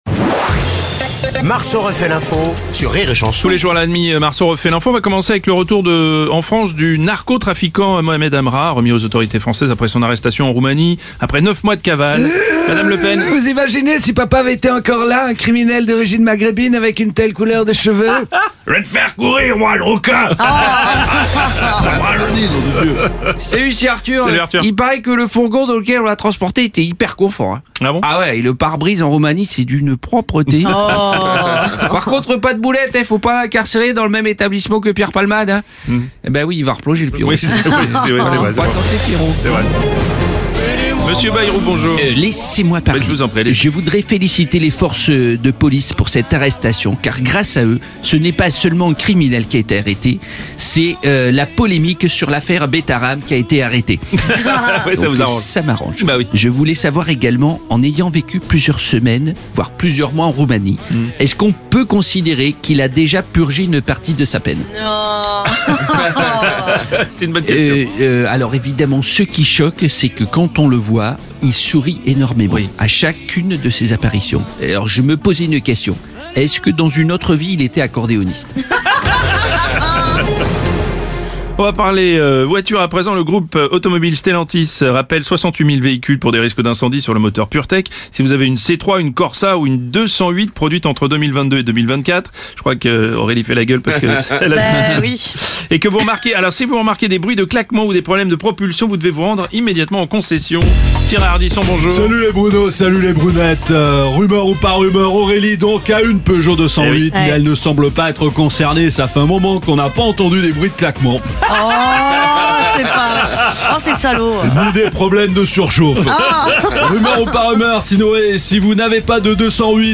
débriefe l’actu en direct à 7h30, 8h30, et 9h30.